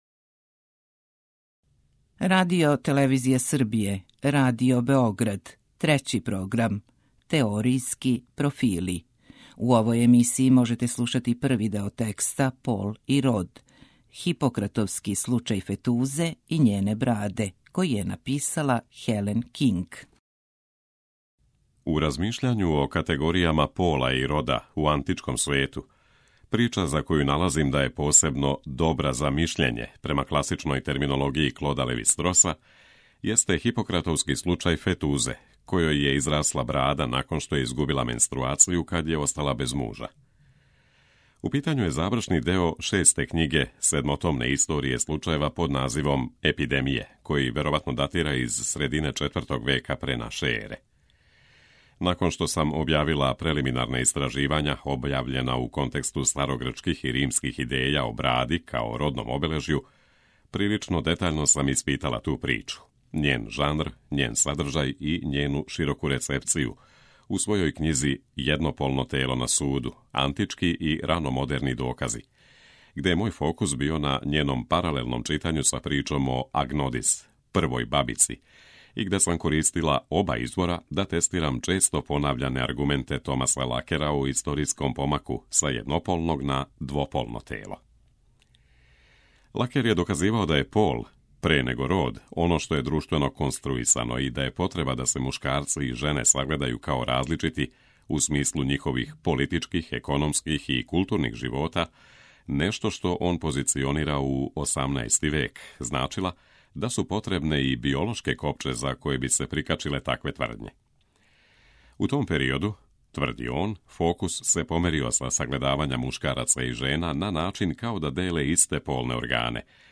У емисијама РЕФЛЕКСИЈЕ читамо есеје или научне чланке домаћих и страних аутора.